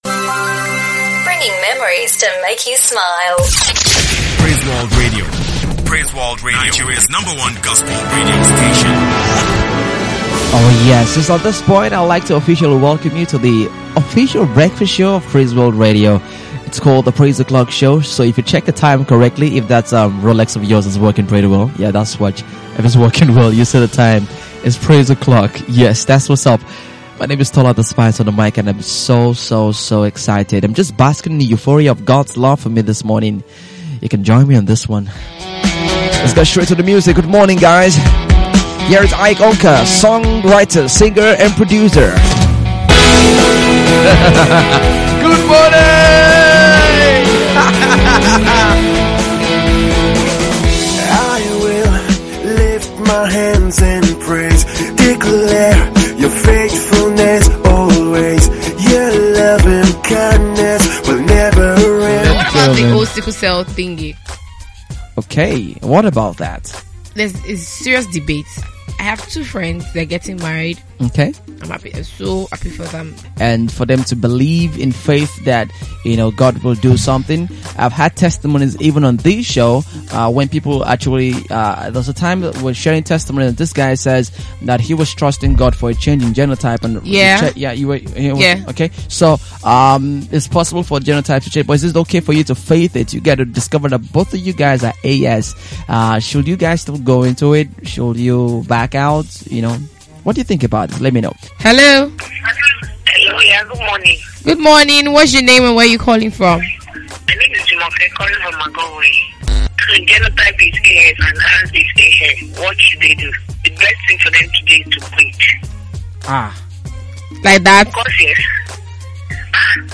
Several callers share their views.